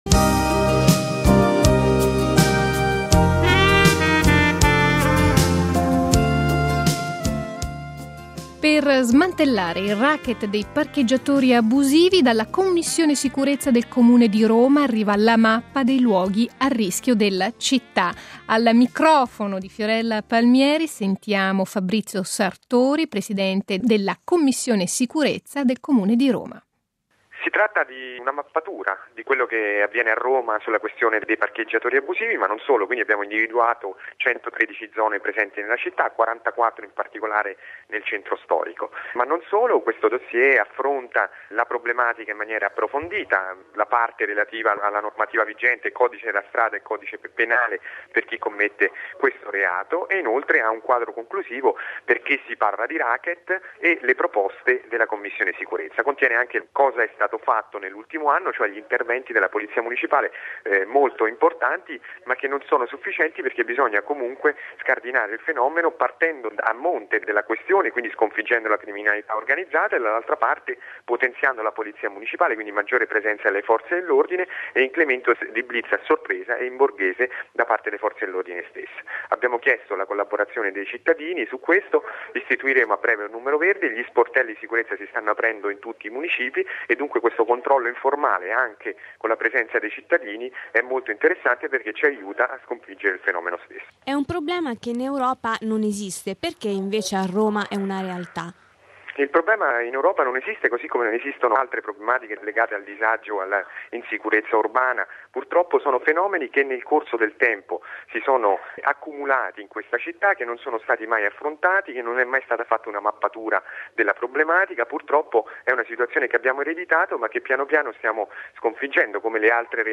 A 105 Live, Fabrizio Sartori, presidente della Commissione indica il potenziamento dei vigili urbani, l'incremento di blitz a sorpresa, anche in borghese, e l' istituzione di un numero verde in ogni municipio come metodi di lotta al fenomeno. Una lotta senza tregua contro un fenomeno esteso e talora violento che intacca la sicurezza dei cittadini e alimenta le casse della criminalità organizzata.